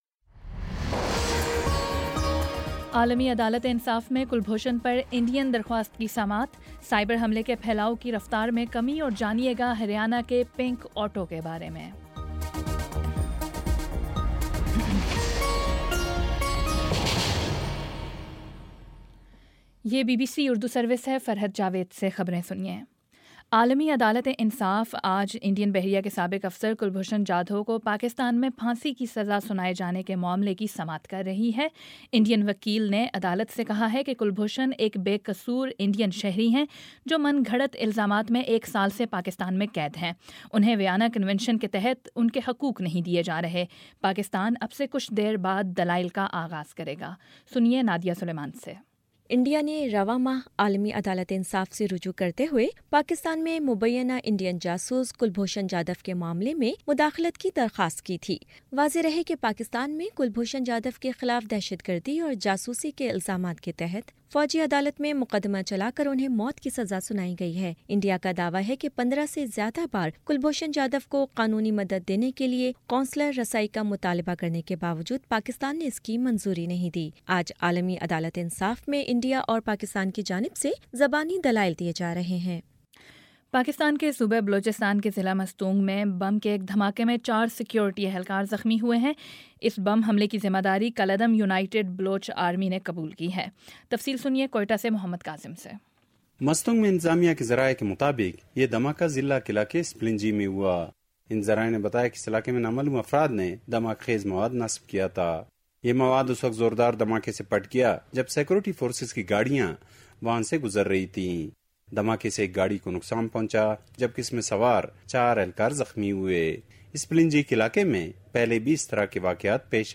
مئی 15 : شام چھ بجے کا نیوز بُلیٹن